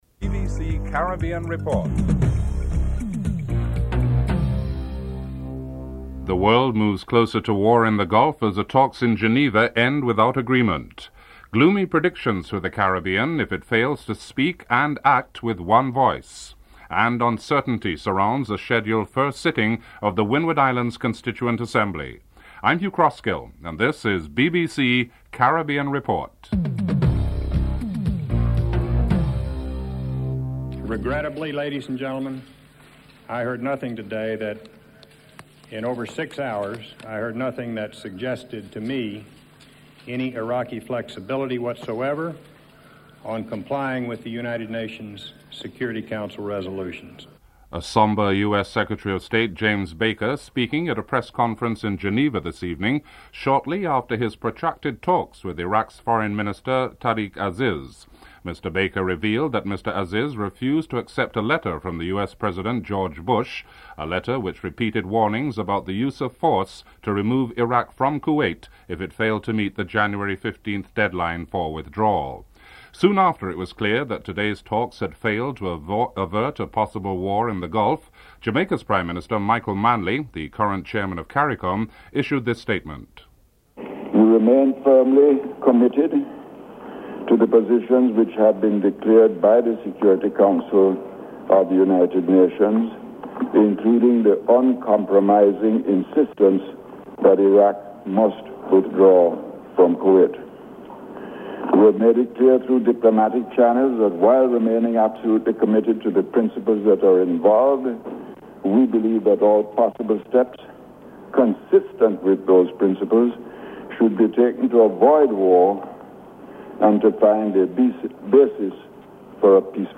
1. Headlines (00:00-00:30)
The Foreign Minister of Belize, Mr. Said Musaif speaks about the benefits of having OAS membership (12:22-15:00)